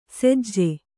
♪ sejje